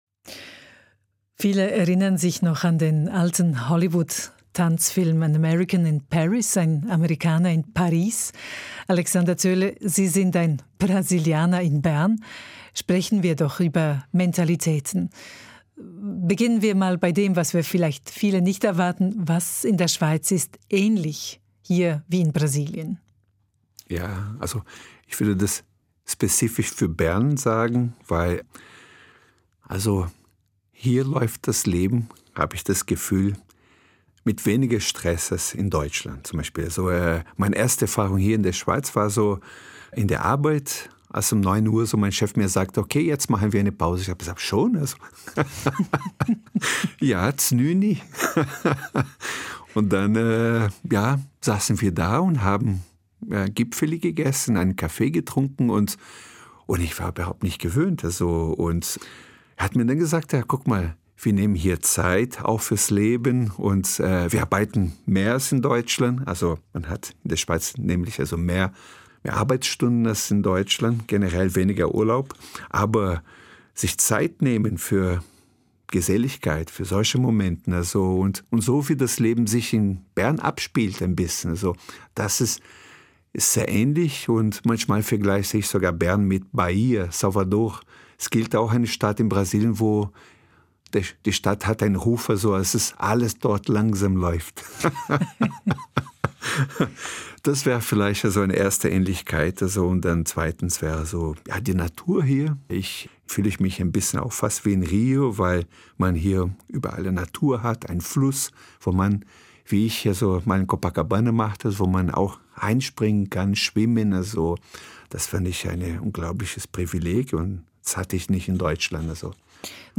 Teil 2 der Gesprächsserie